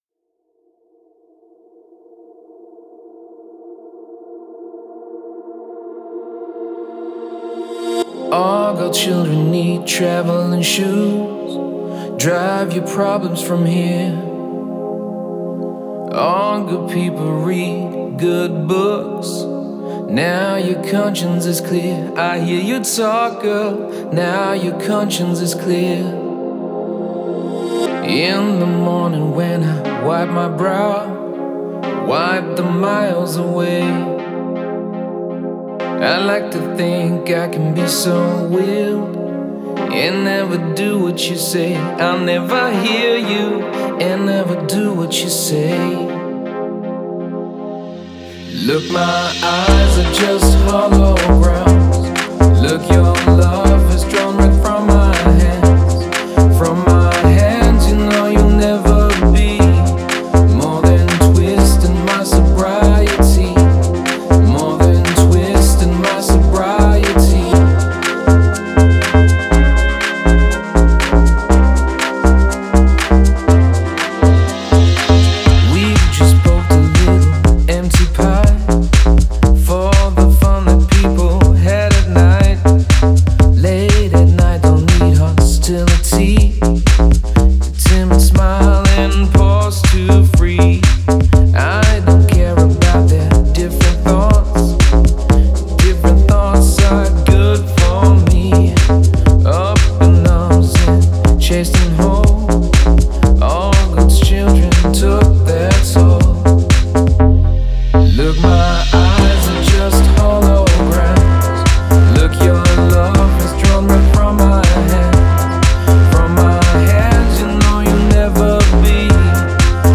Главная » Файлы » Клубная Музыка Категория